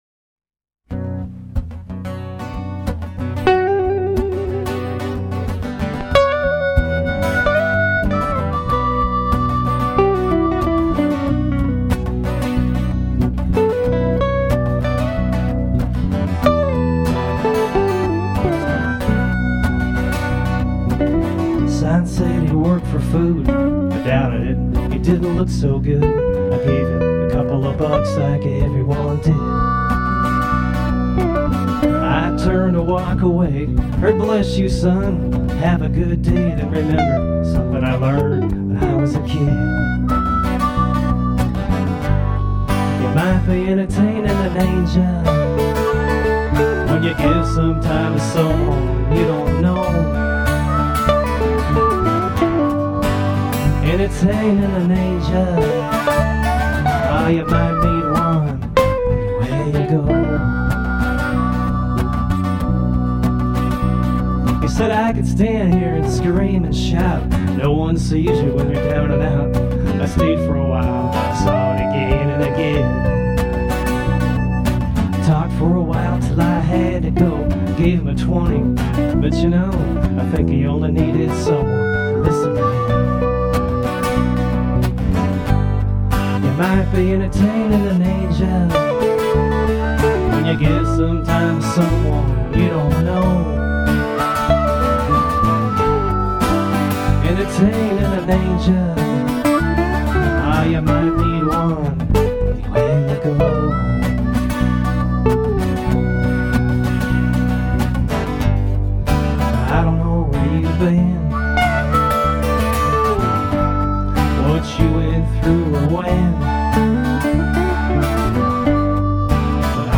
came across live in the store.